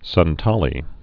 (sŭn-tälē)